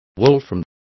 Complete with pronunciation of the translation of wolfram.